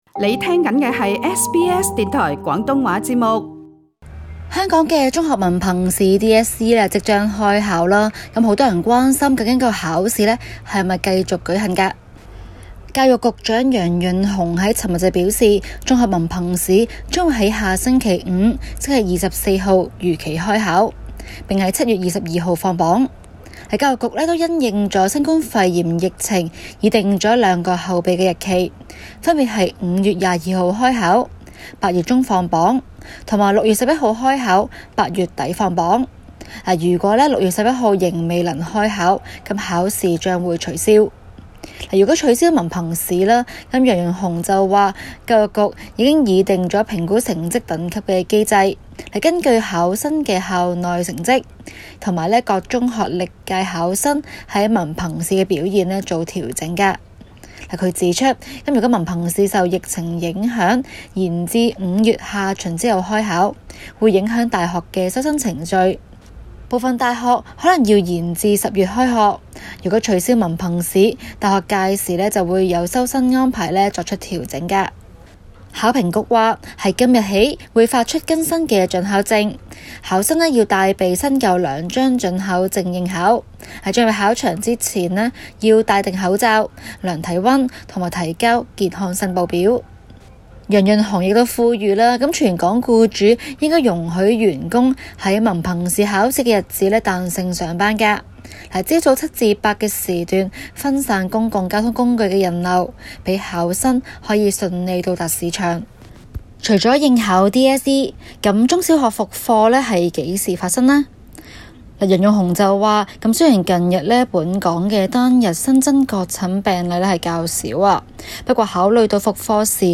今期 【中港快訊 】環節報導特區政府有意照原定計劃本月24日舉行中學文憑試。